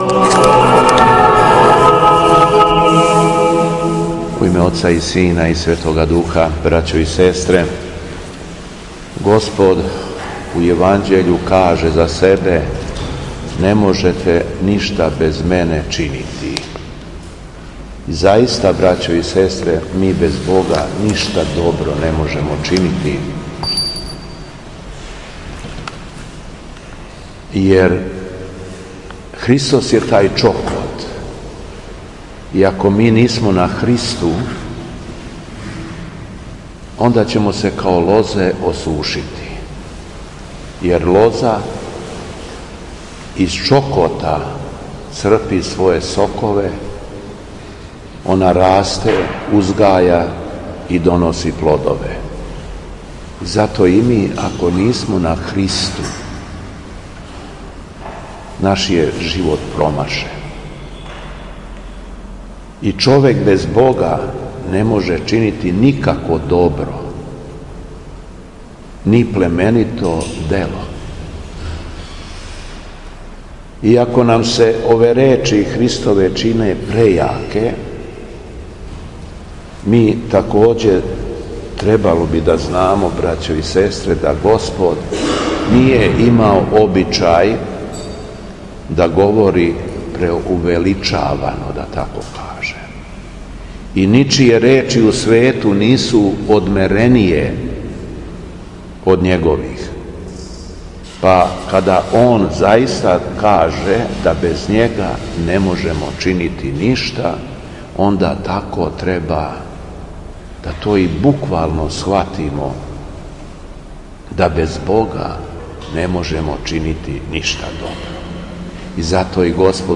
Беседа Његовог Преосвештенства Епископа шумадијског г. Јована